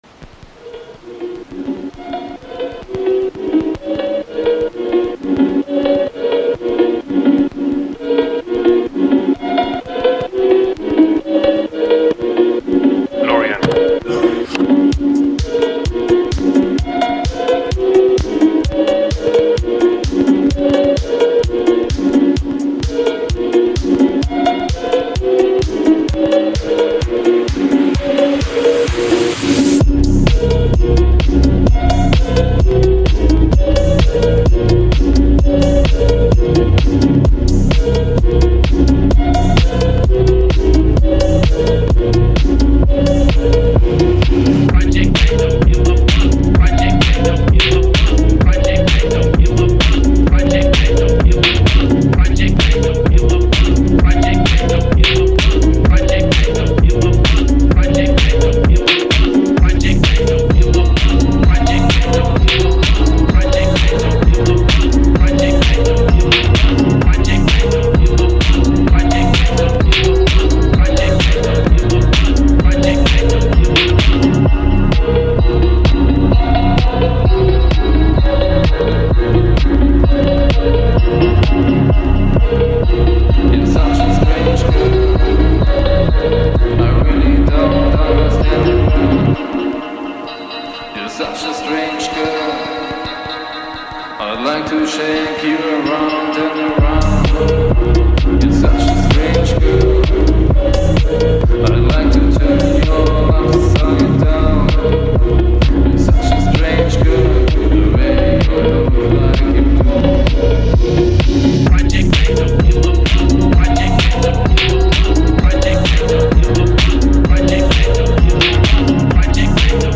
the_perfect_phonk_.mp3